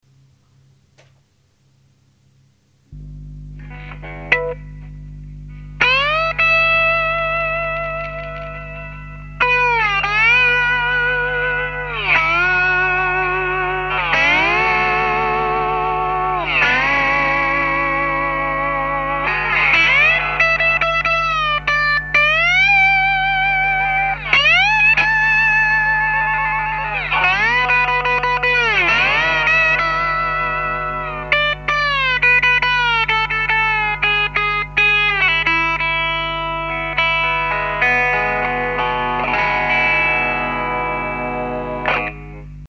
bottleneck01.mp3